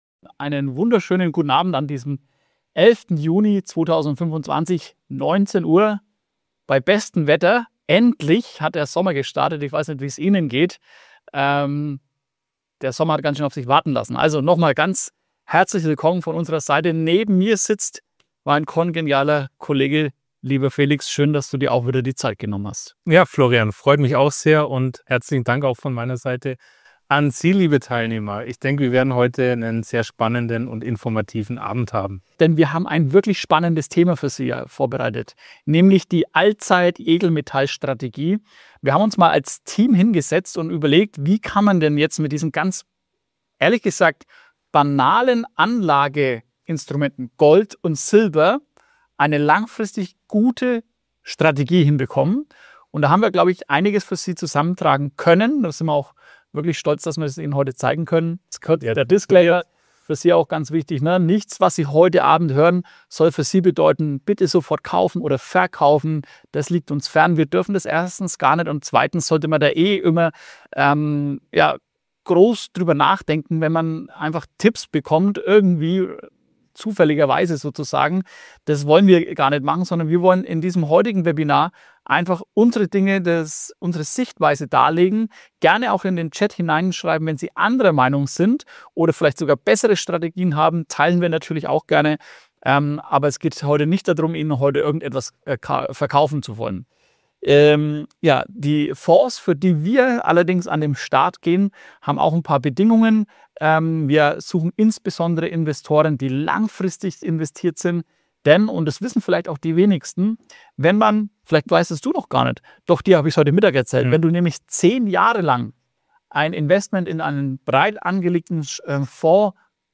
Im Rahmen des Webinars haben die Experten auf Fragen des Publikums beantwortet.